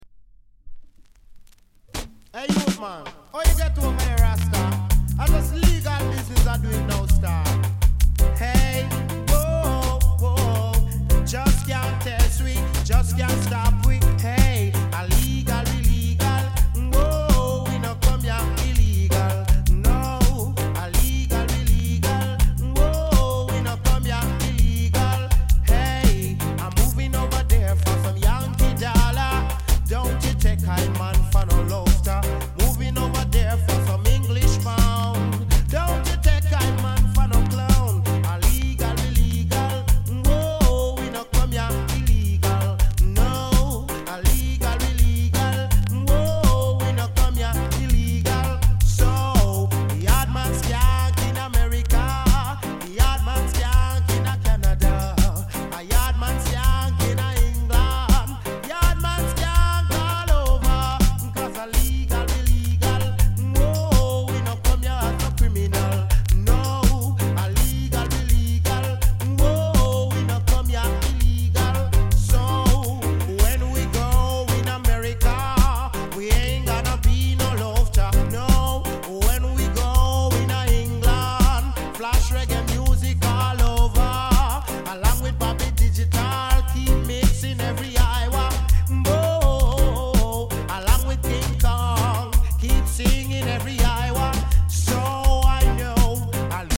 概ね美盤ですが、B面 少しパチつく箇所あり。